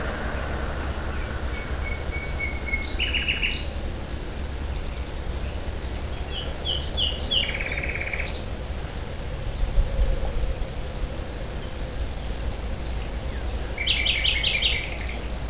Abb. 09: Nachtigall, verschiedene Melodiefolgen.